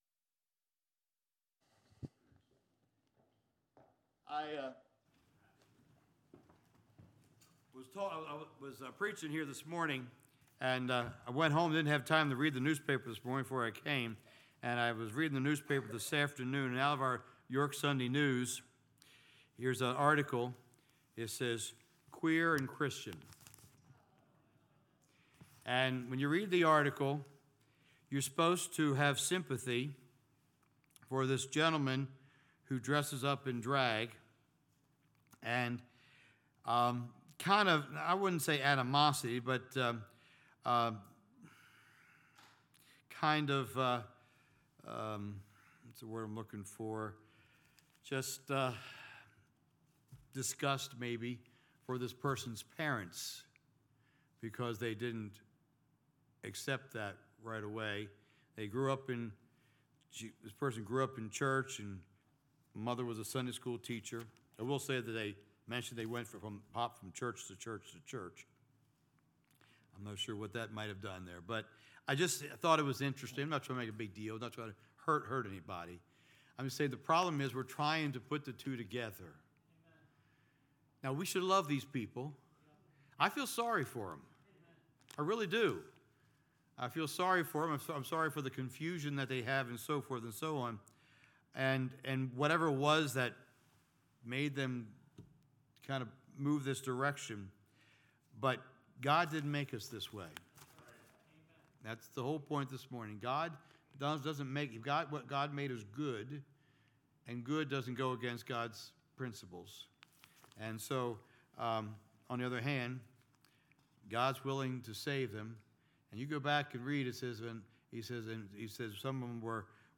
Revelation 3:d1-6 Service Type: Sunday Evening « God Made Me This Way Spiritual Gray Areas